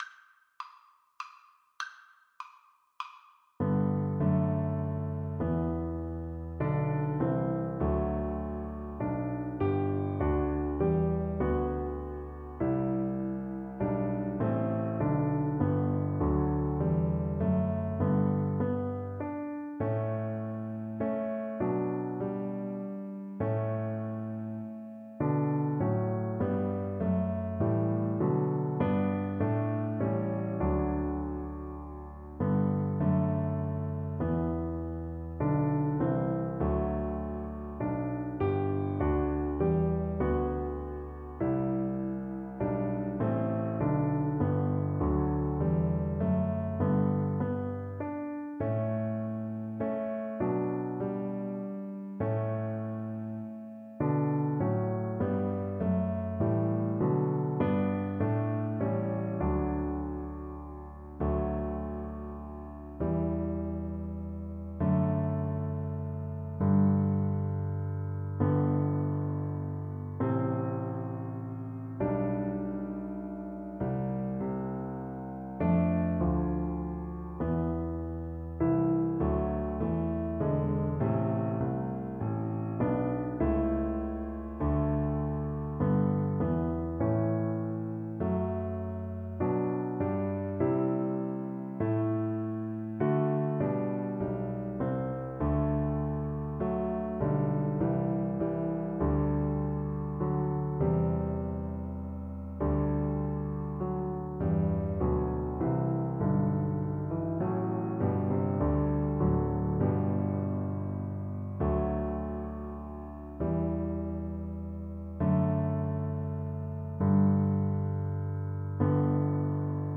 Cello version
3/4 (View more 3/4 Music)
Classical (View more Classical Cello Music)